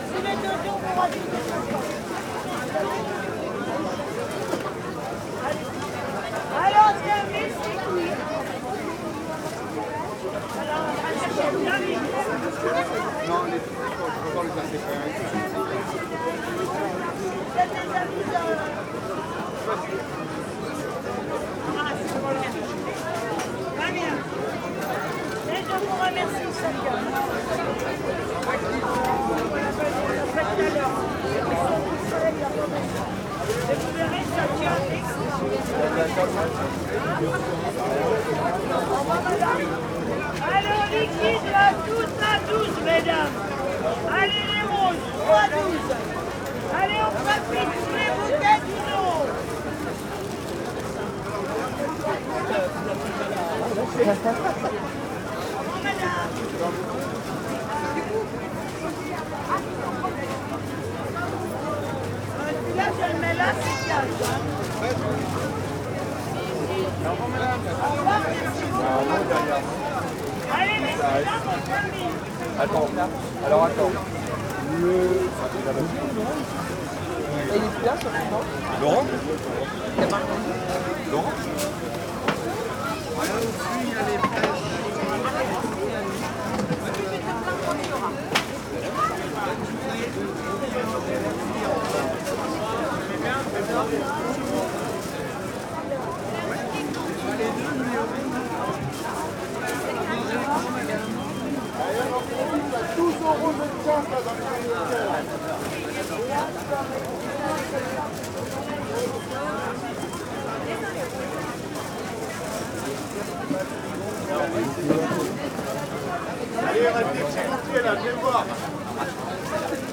Outdoor Market #3
Market day in Neuilly-sur-Seine. People speak French and I wander between the stalls.
UCS Category: Ambience / Market (AMBMrkt)
Type: Soundscape
Channels: Stereo
Disposition: ORTF
Conditions: Outdoor
Equipment: SoundDevices MixPre-3 + Neumann KM184